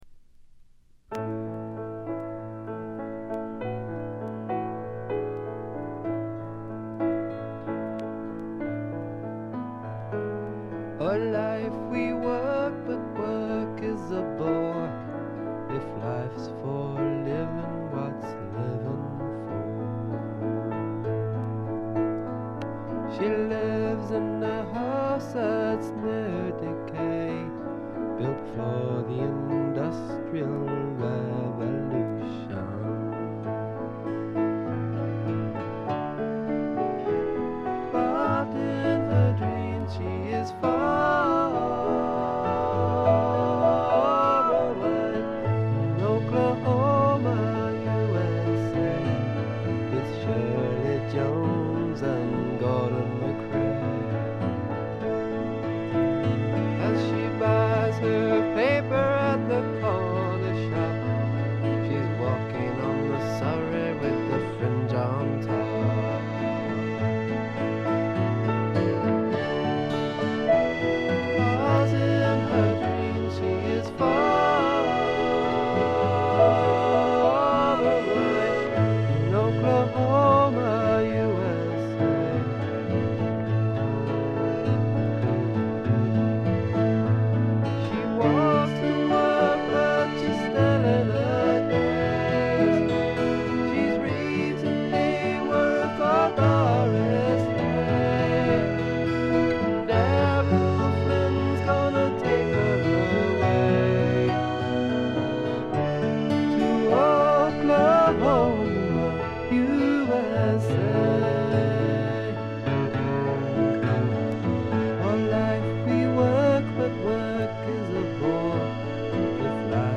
これ以外は軽微なバックグラウンドノイズに散発的なプツ音少し。
試聴曲は現品からの取り込み音源です。